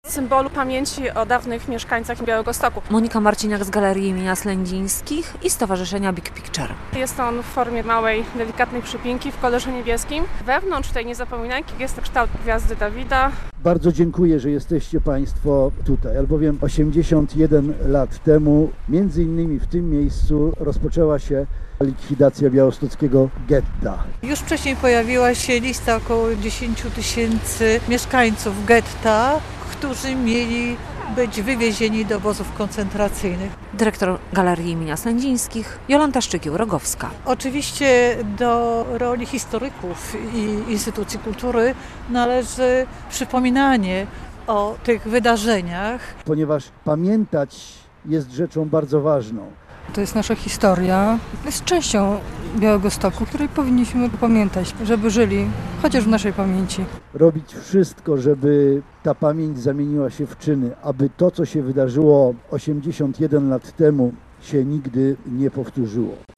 81. rocznica likwidacji białostockiego getta - relacja